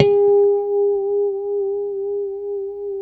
A4 PICKHRM1B.wav